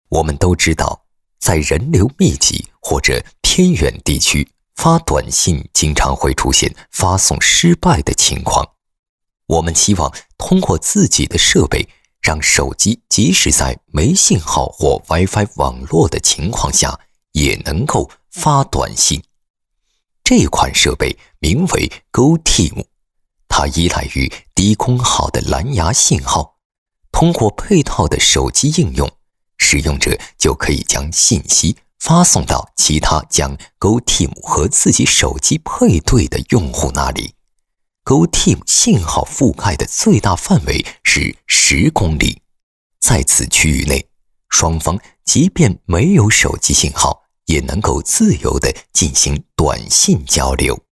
【专题】goTema男73-磁性讲诉
【专题】goTema男73-磁性讲诉.mp3